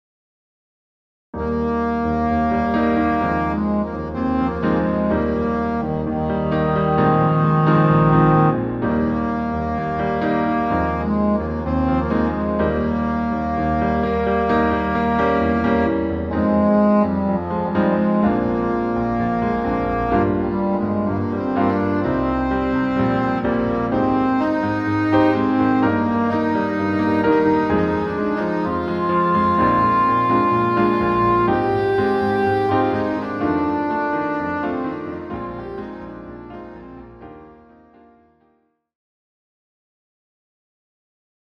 the clarinet version is entirely within the low register
A firm rock-feel beat gives this piece energy.
Key: E flat major